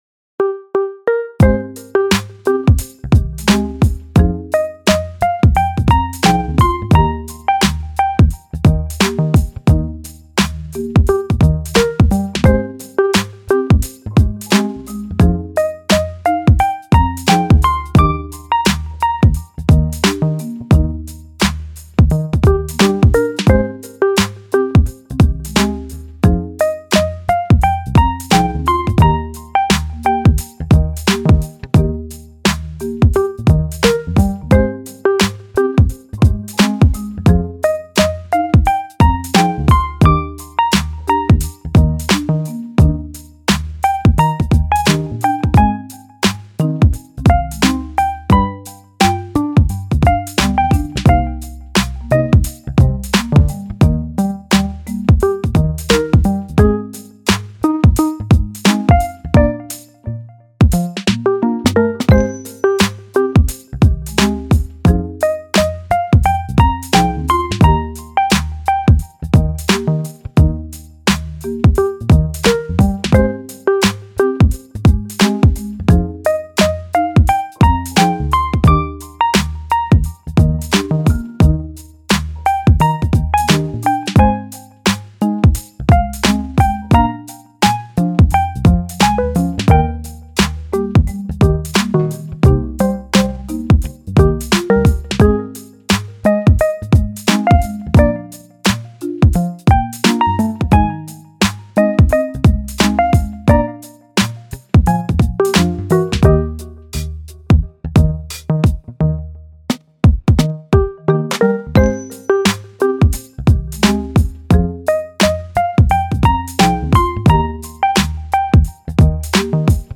チル・穏やか
明るい・ポップ